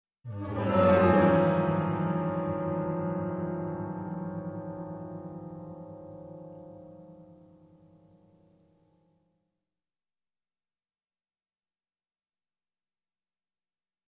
Ужасы звуки скачать, слушать онлайн ✔в хорошем качестве